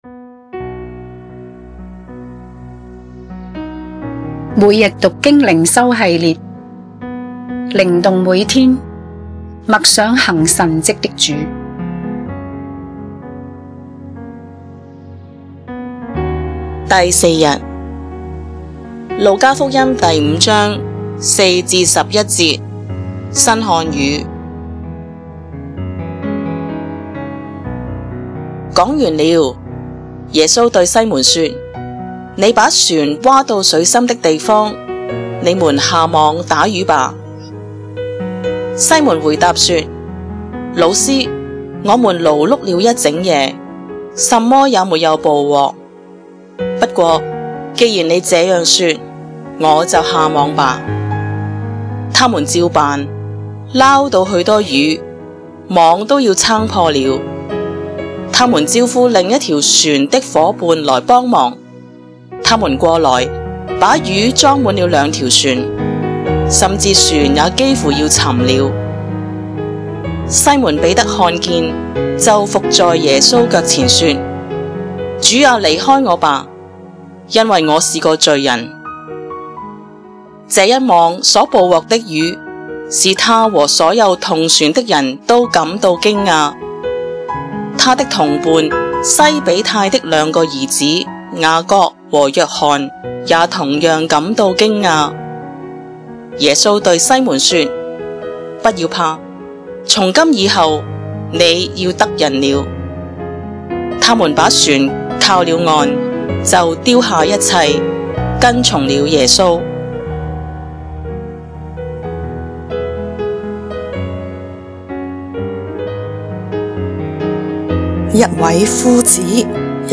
經文閱讀